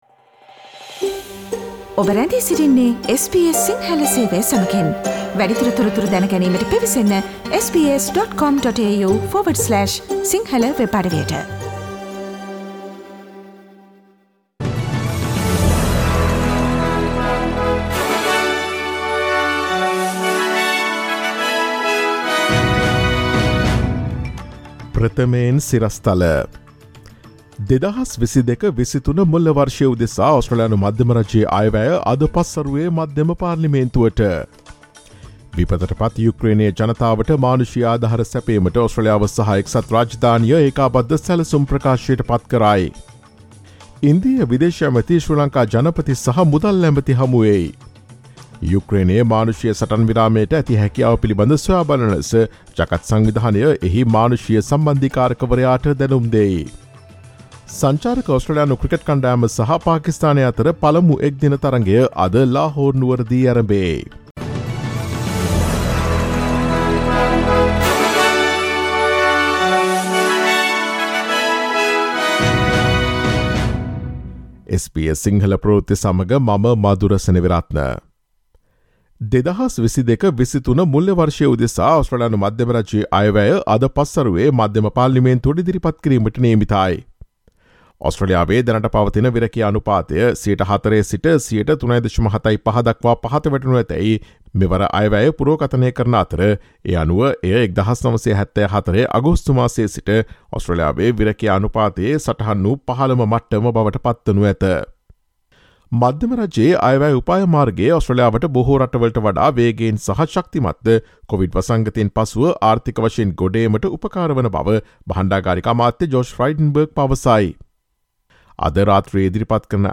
ඔස්ට්‍රේලියාවේ සහ ශ්‍රී ලංකාවේ නවතම පුවත් මෙන්ම විදෙස් පුවත් සහ ක්‍රීඩා පුවත් රැගත් SBS සිංහල සේවයේ 2022 මාර්තු 29 වන දා අඟහරුවාදා වැඩසටහනේ ප්‍රවෘත්ති ප්‍රකාශයට සවන් දීමට ඉහත ඡායාරූපය මත ඇති speaker සලකුණ මත click කරන්න.